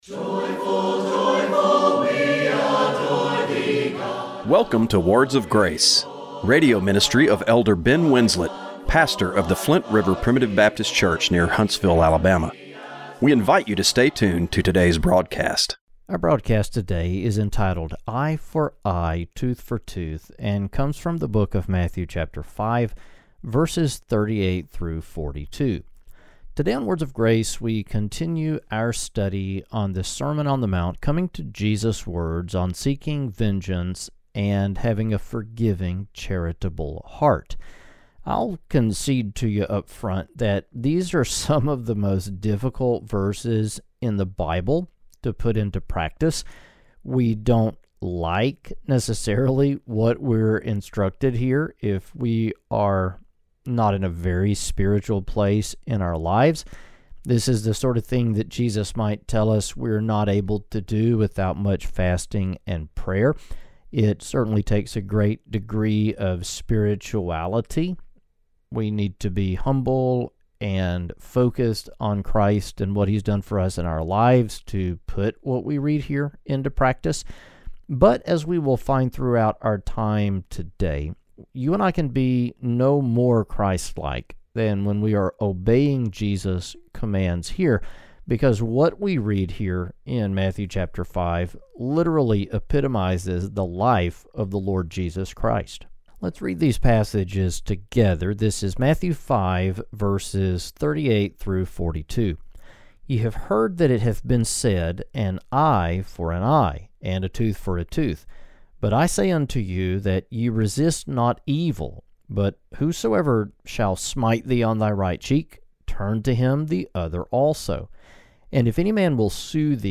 Radio broadcast for June 22, 2025.